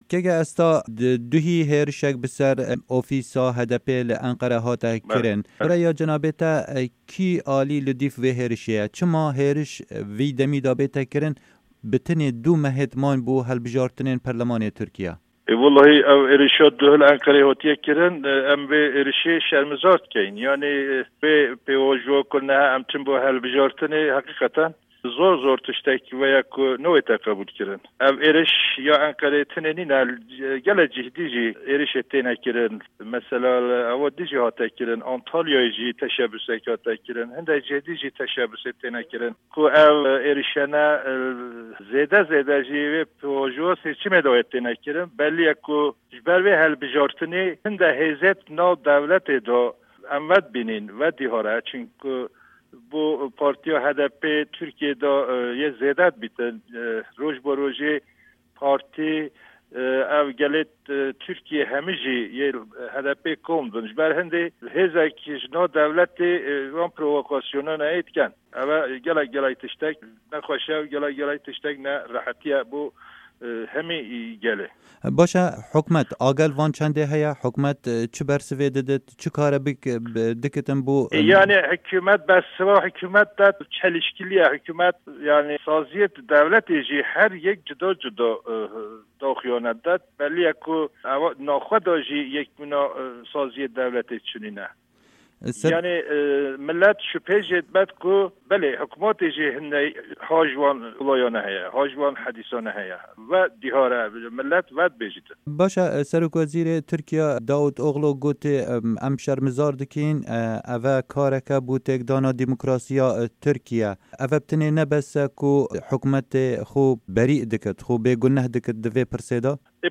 Endamê parlamena Tirkiyê û nûnerê Hekarî Esta Cenan di hevpeyvînekê de ligel Dengê Amerîka dibêje, xuya ye hindek hêzên di nav dewletê de dest di vê êrîşê da heye.
Hevpeyvin digel Esta Cenan